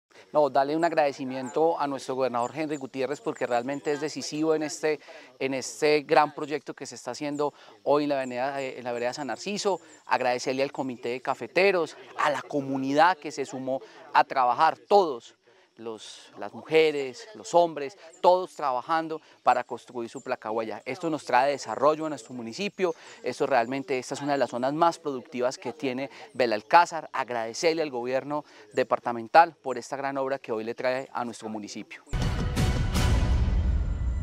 Fabio Andrés Ramírez, alcalde de Belalcázar.